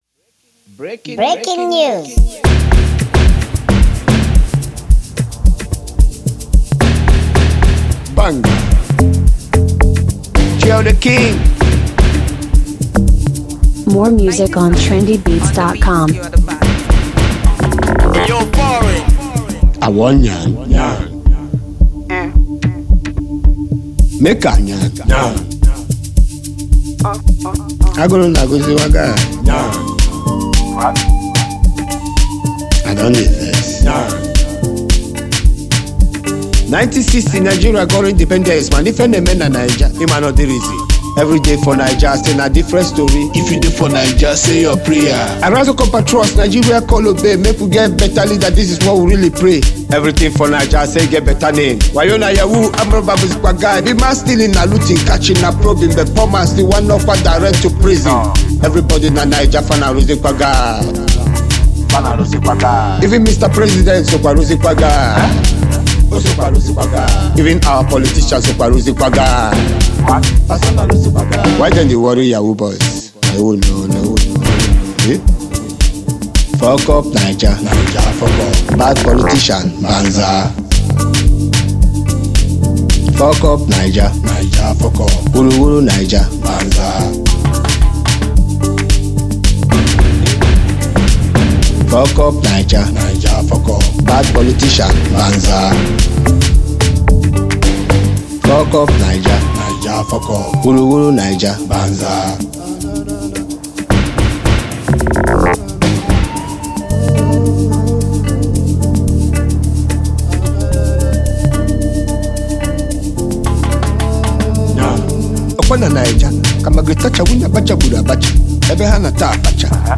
Skilled music singer